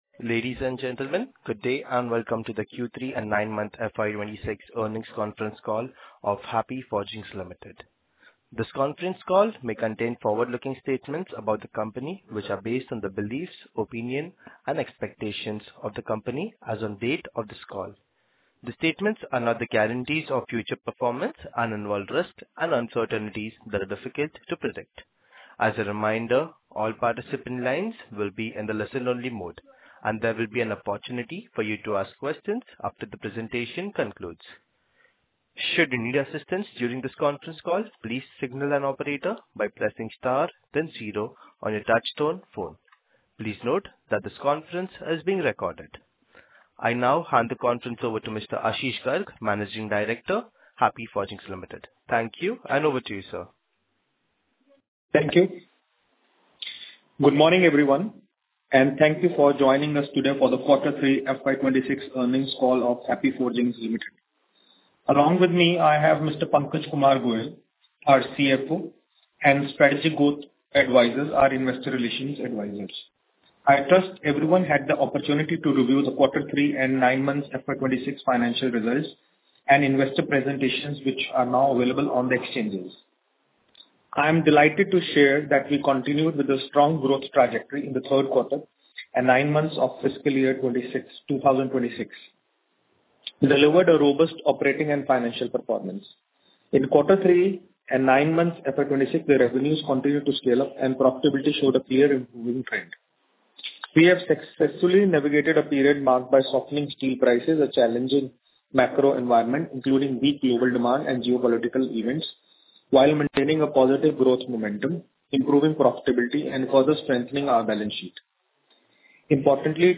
Concalls
Earnings-Call-Audio-Q3FY26.mp3